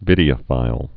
(vĭdē-ə-fīl)